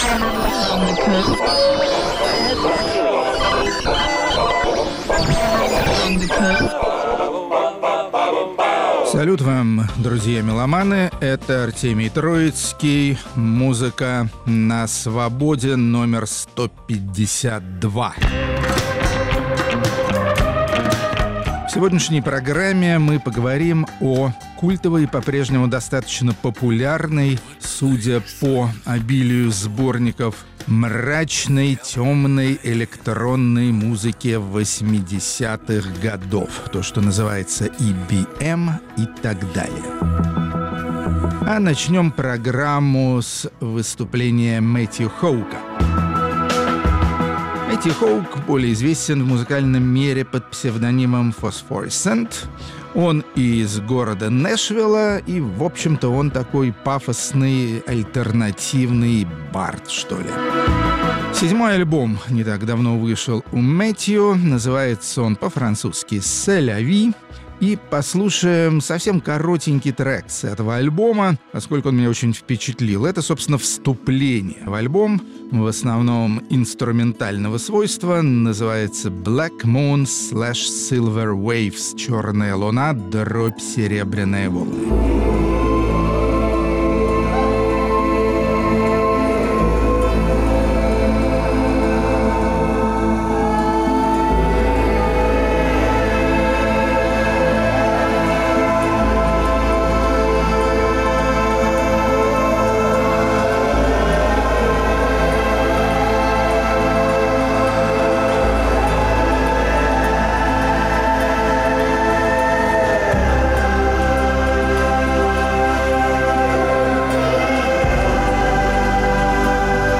Музыка на Свободе. 12 апреля, 2020 Энергичные исполнители, в 1980-е годы работавшие в стиле "музыки электронного тела". Рок-критик Артемий Троицкий считает такого рода творческие поиски небессмысленными: музыкальный коктейль получился забористый.